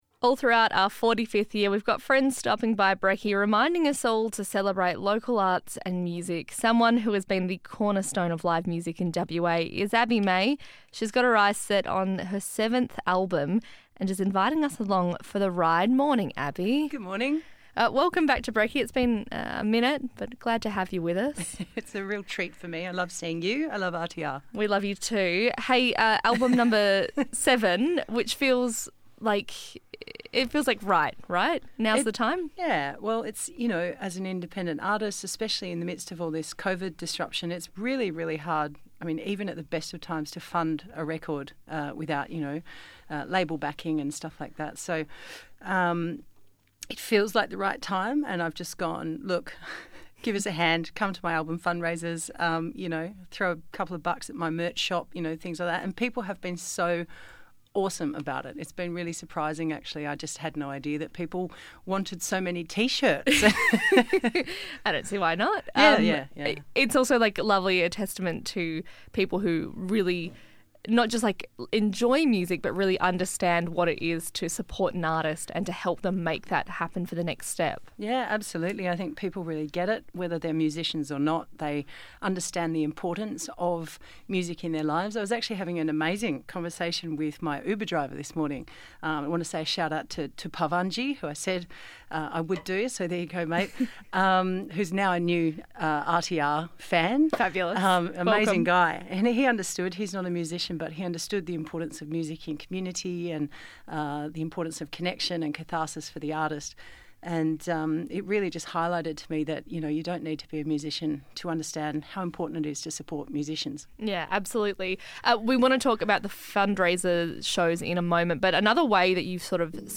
All throughout our 45th year we’ve got friends stopping by the studio, reminding us all to celebrate local music and arts.